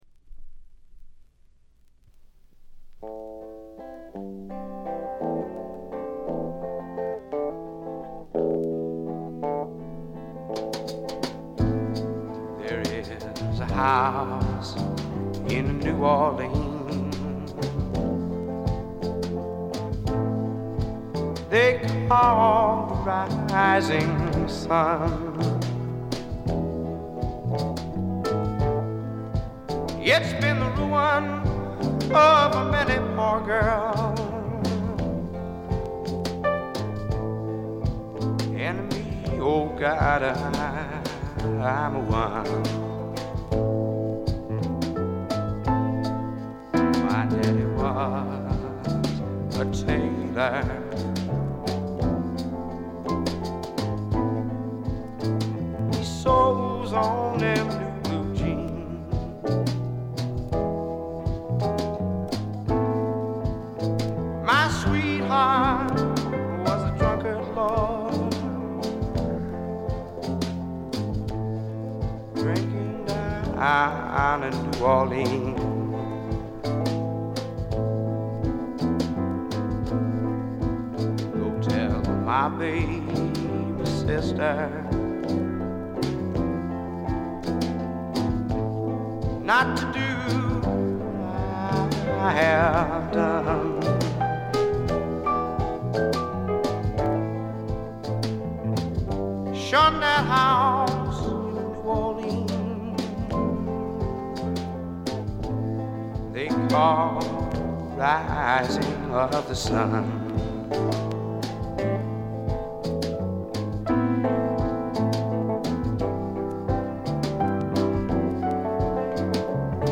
デモと言ってもブルース、R&B色が色濃い素晴らしい演奏を聴かせます。
試聴曲は現品からの取り込み音源です。